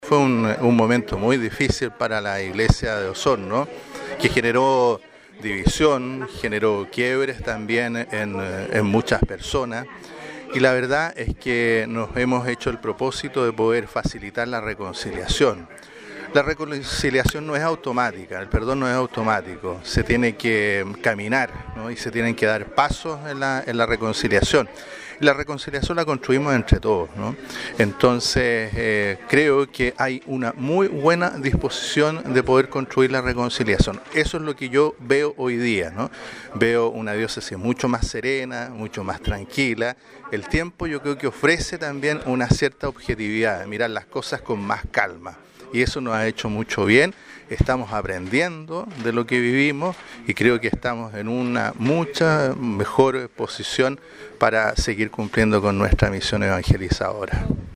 Una mañana fraterna y compartida vivieron representantes de medios de comunicación de Osorno con el obispo de la diócesis católica, monseñor Carlos Godoy Labraña.